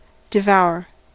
devour: Wikimedia Commons US English Pronunciations
En-us-devour.WAV